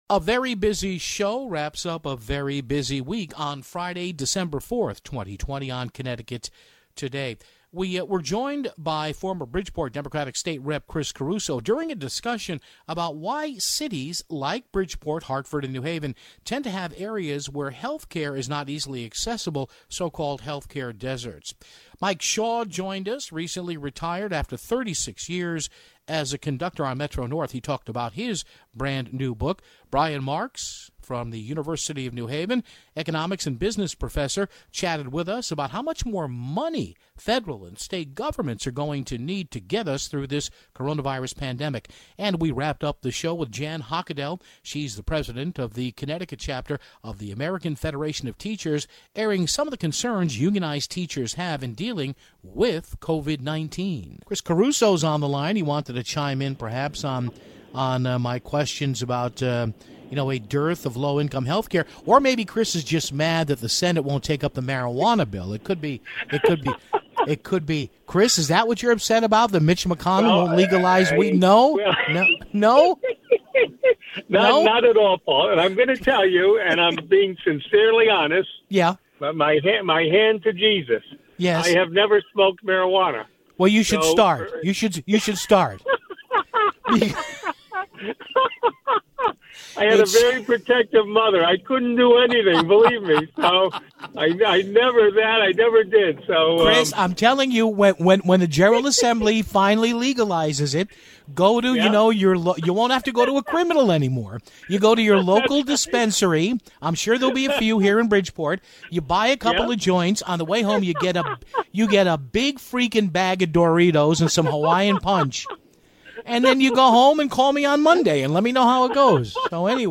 First off, former Bridgeport State Representative Chris Caruso called in to talk about healthcare in bigger cities (0:54).